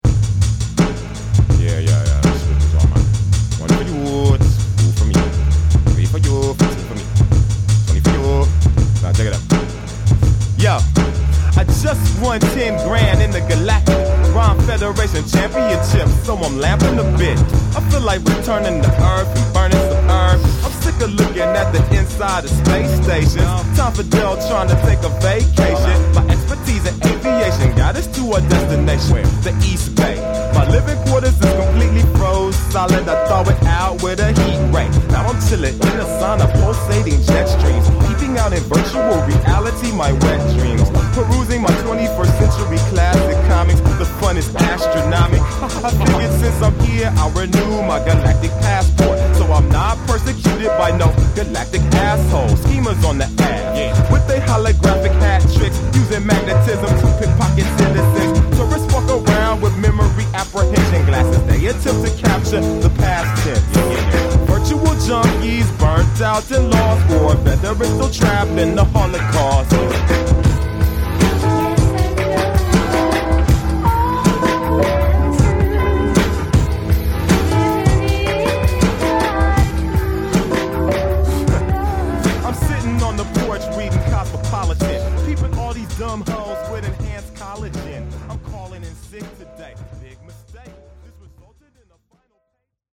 lacing scratches and vocal samples throughout every song